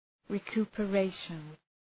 Προφορά
{rı,ku:pə’reıʃən}